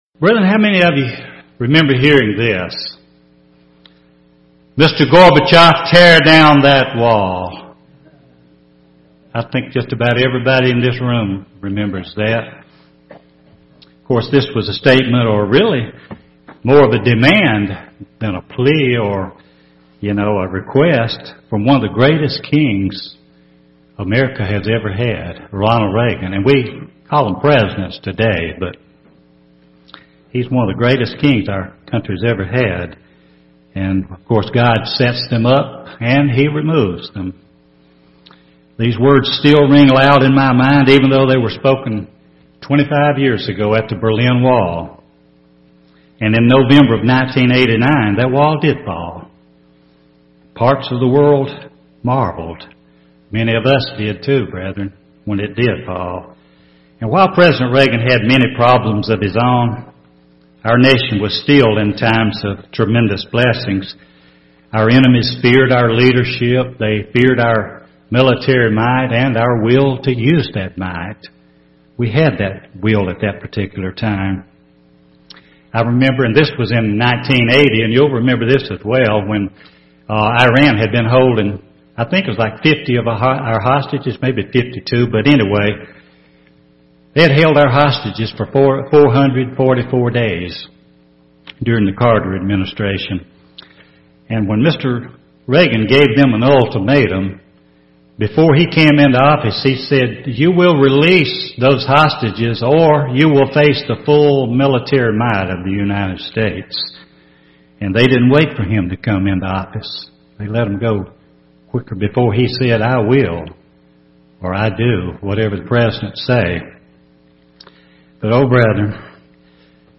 Given in Birmingham, AL
UCG Sermon Studying the bible?